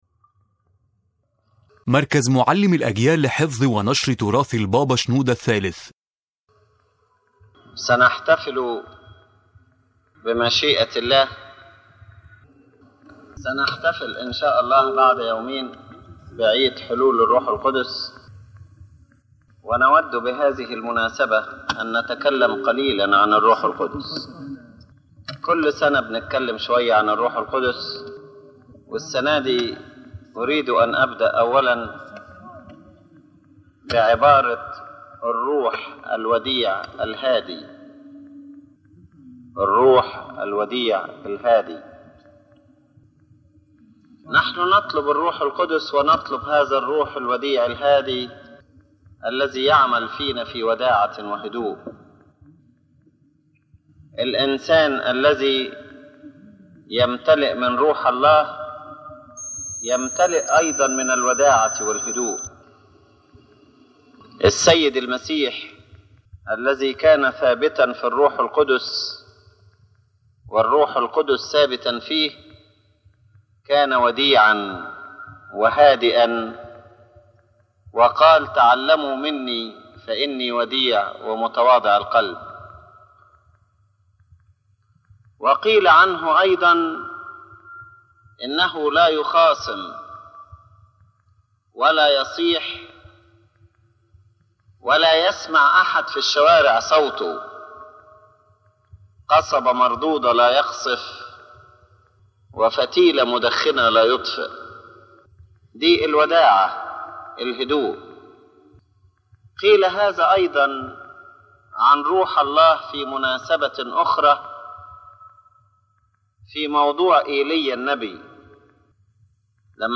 Introduction and General Message His Holiness Pope Shenouda III explains that the Holy Spirit is the spirit of meekness, calmness, and simplicity, and that whoever possesses the Spirit of God shows meekness and serenity. The general message of the lecture calls believers to adopt the gentle and guiding Spirit as the foundation of spiritual life and Christian behavior.